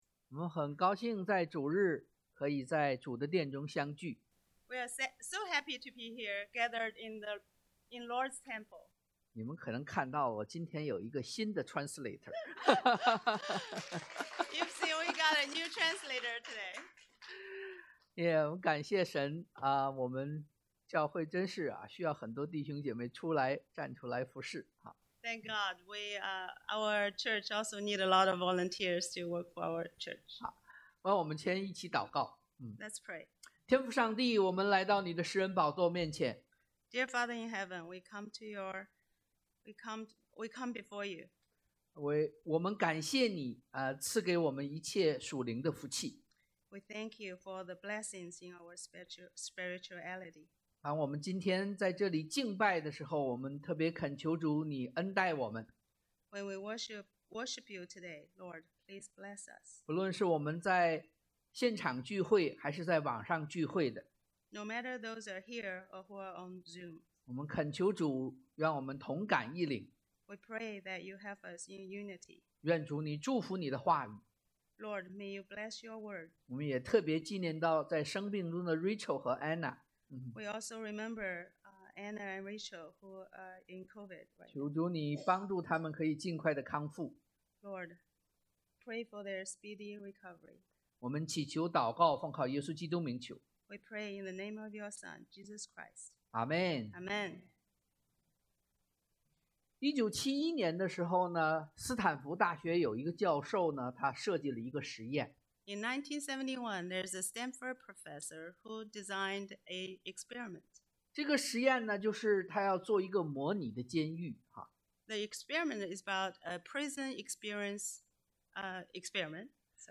Sermon title: 涇渭分明 The Incompatible Passage: 約壹 1 John 2:28-3:10 Outline:
Service Type: Sunday AM